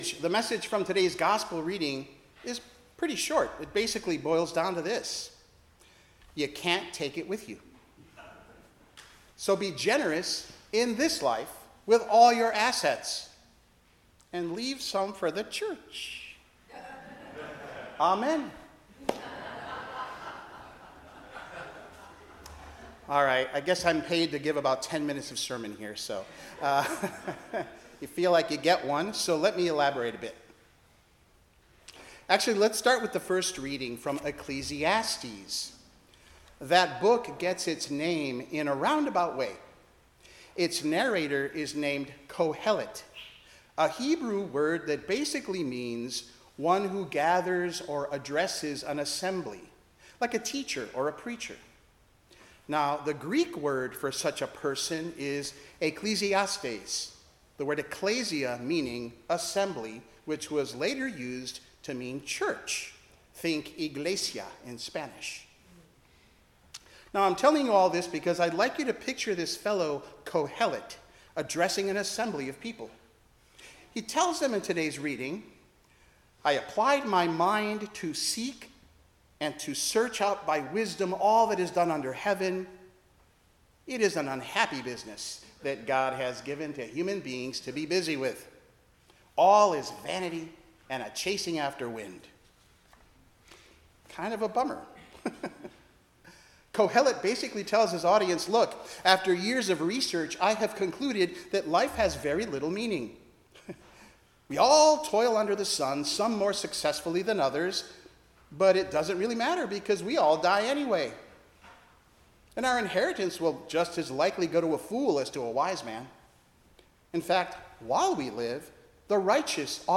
Passage: Ecclesiastes 1:2,12-14; 2:18-23, Psalm 49:1-11, Colossians 3:1-11, Luke 12:13-21 Service Type: 10:00 am Service